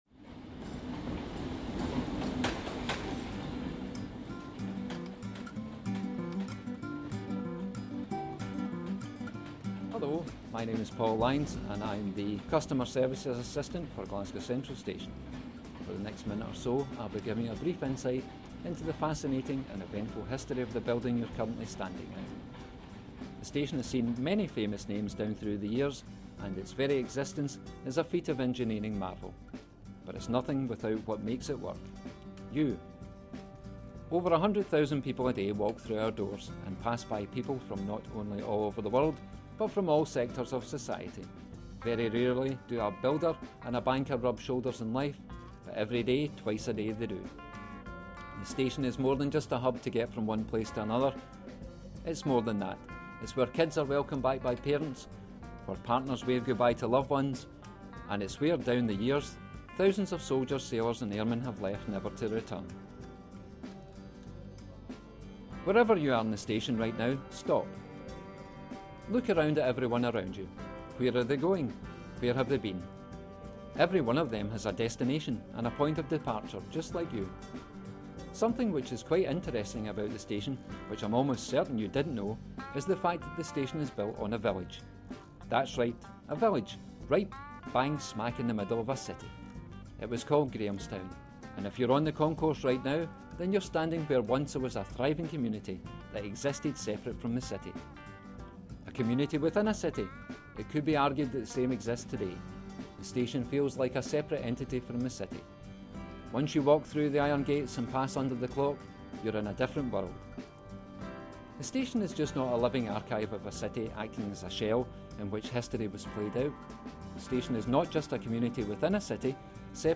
Music Bed: ‘See You Later’, by Pitx Creative Commons License